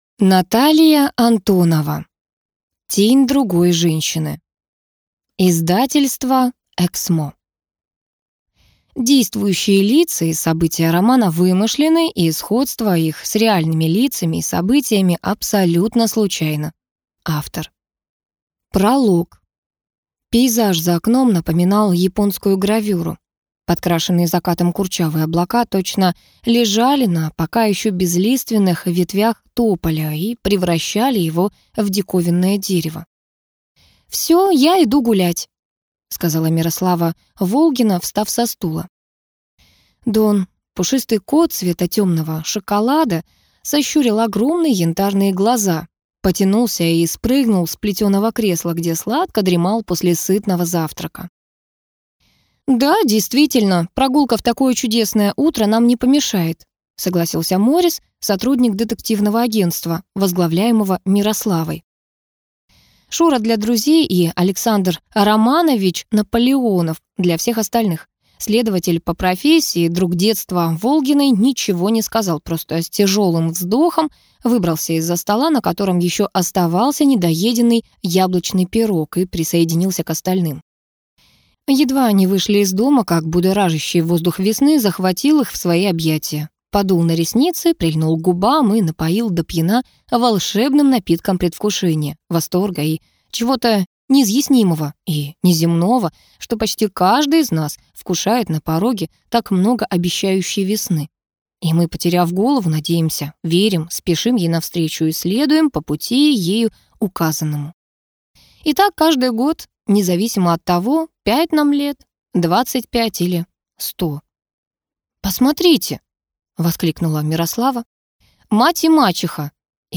Аудиокнига Тень другой женщины | Библиотека аудиокниг